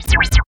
84 NOISE  -L.wav